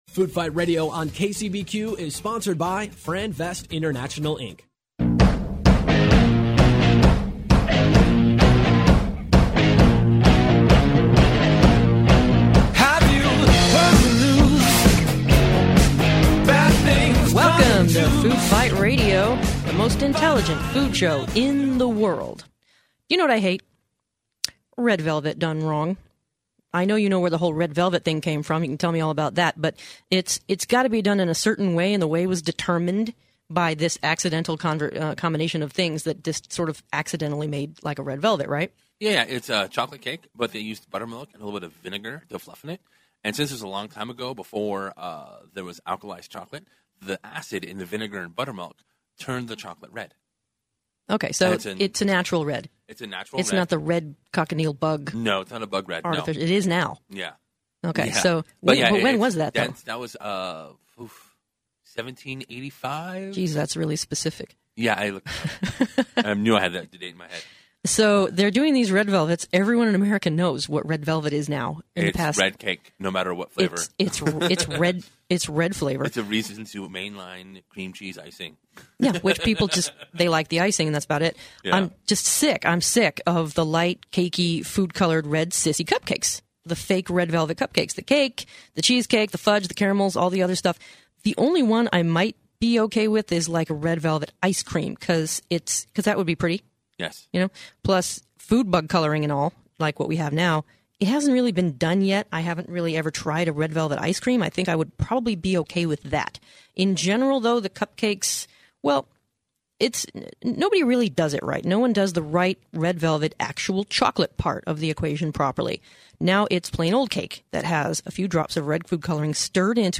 What to do with that leftover avocado Interview with Sweet Vybz cupcakes